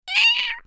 جلوه های صوتی
دانلود صدای گربه کوچک از ساعد نیوز با لینک مستقیم و کیفیت بالا